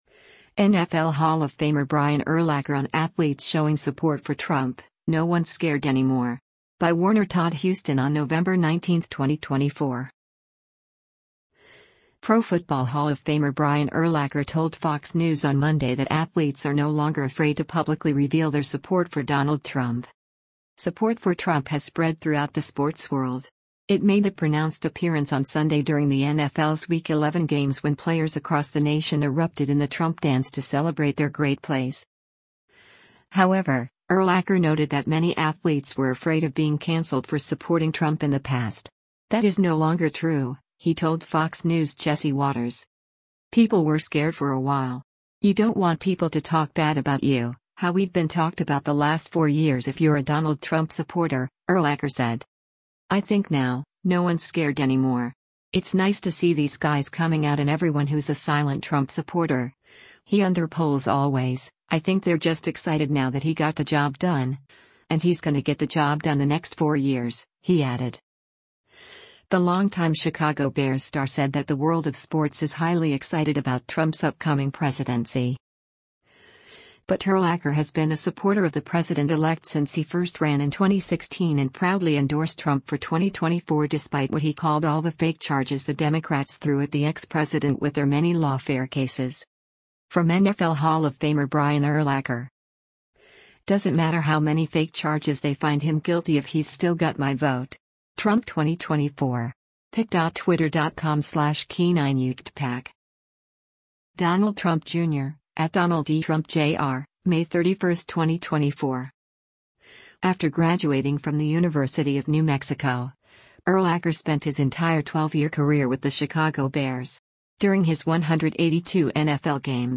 That is no longer true, he told Fox News’ Jesse Watters.